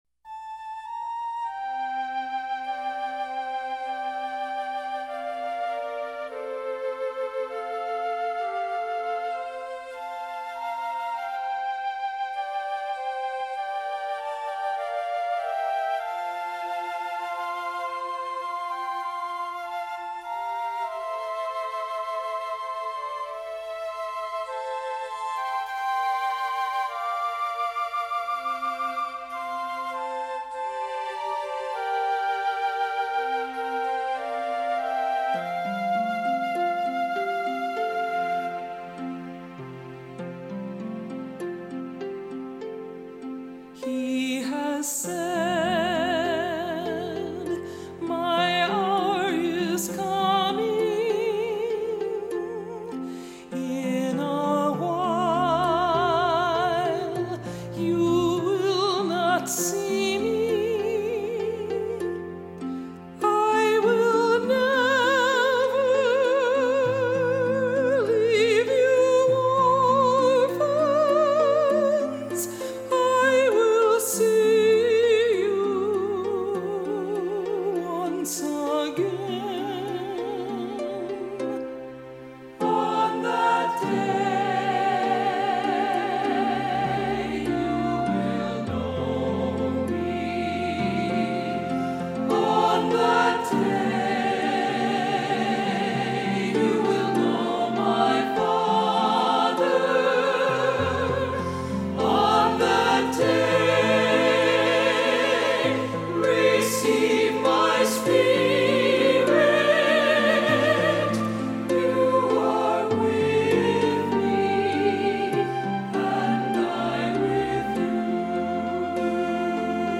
Voicing: Cantor,Assembly